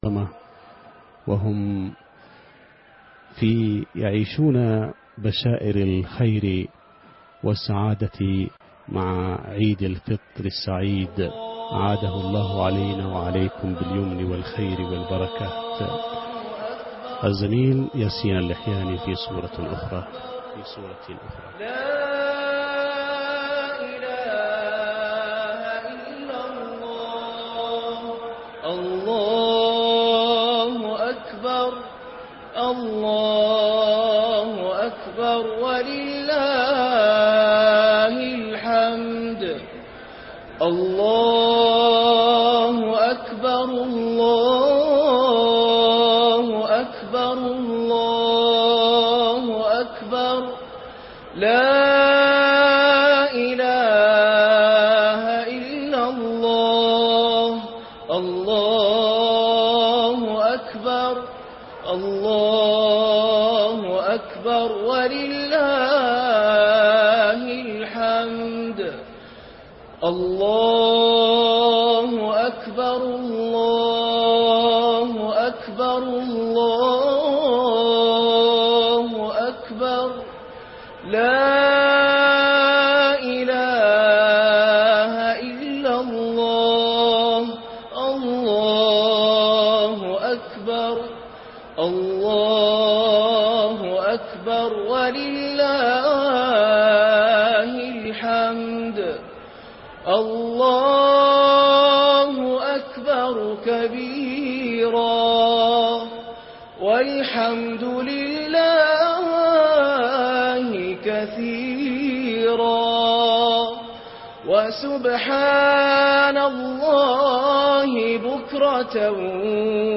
صلاة العيد من الحرم المكي( 28/7/2014) - قسم المنوعات